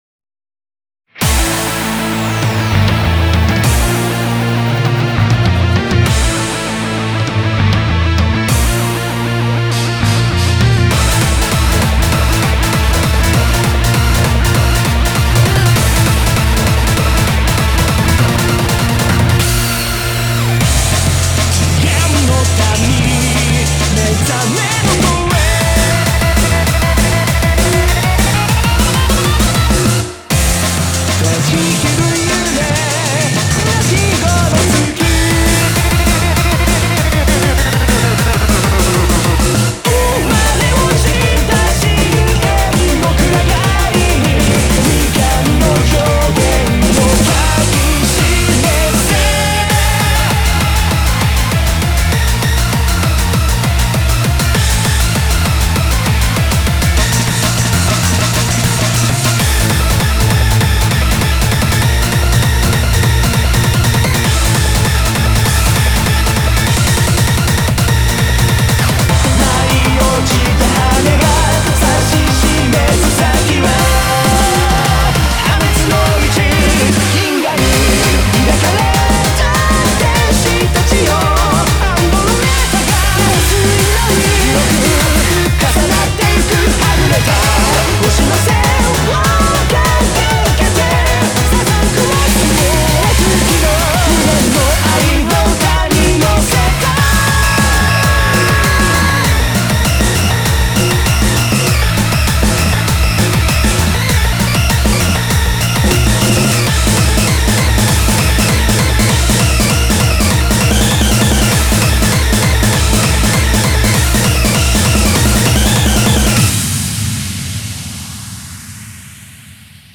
BPM198
Audio QualityPerfect (High Quality)
Mashup